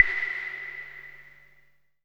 34 V.CLAVE-R.wav